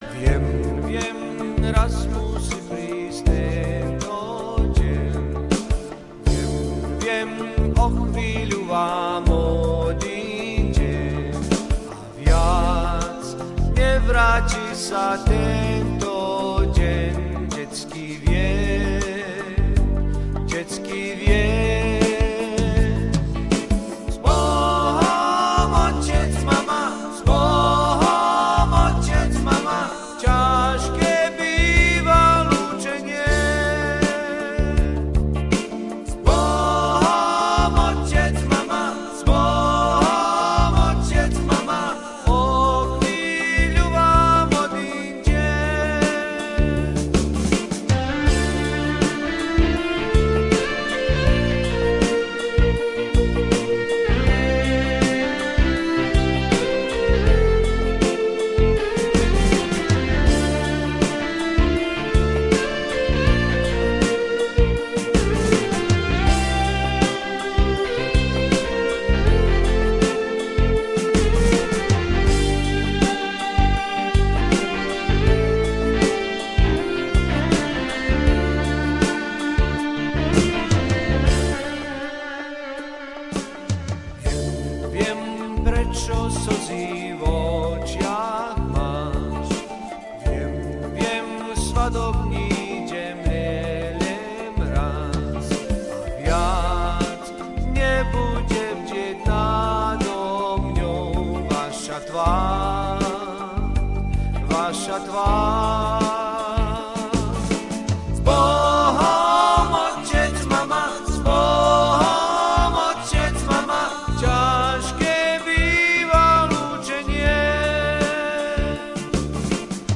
Reportáž z osláv 50. výročia založenia Slovenského spolku Ľudovíta Štúra v Melbourne v Slovenskom dome v Lavertone 22. októbra 2016